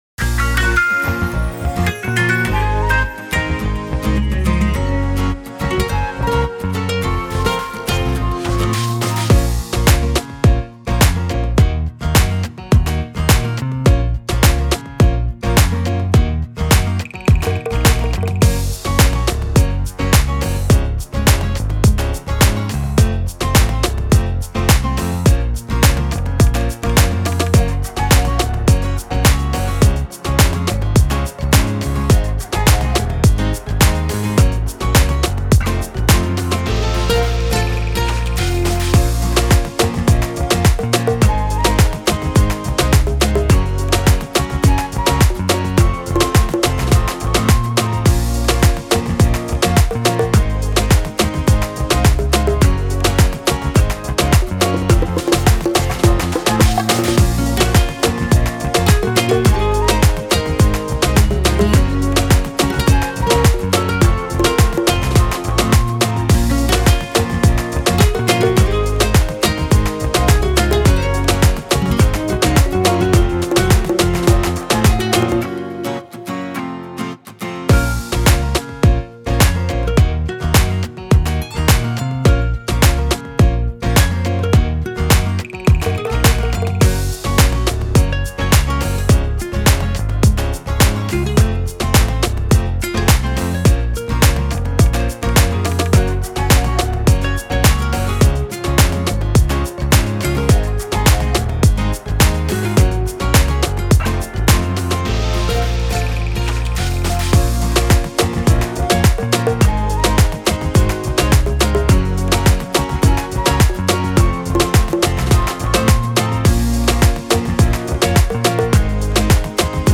Оцените, пожалуйста, инструментал..
Считаю, получилось достаточно современно, а вы как считаете?